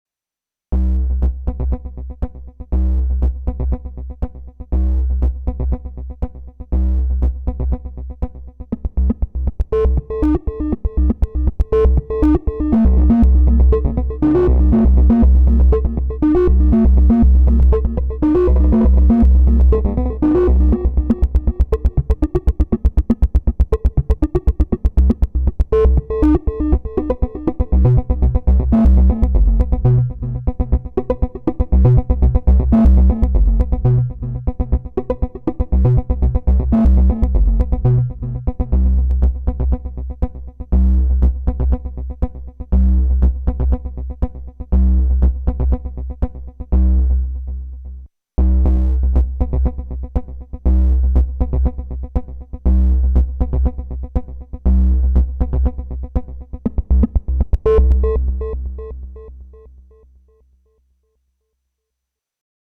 Back on the MK1, I ran the CV out into the audio INs and and made this by generating tones only from the digital LFO at audio rates. No zappy in this case at least.
Not saying it’s spectacular, but it only makes use of the CV track, leaving the 4 main tracks free.